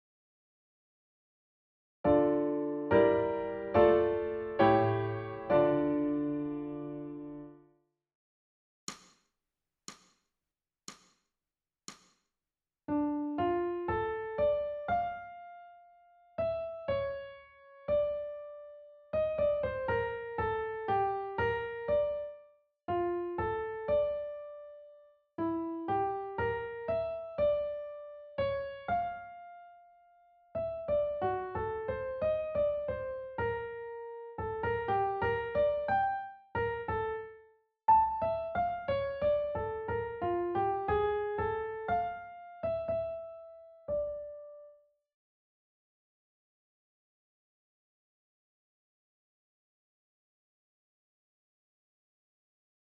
ソルフェージュ 聴音: 2-1-31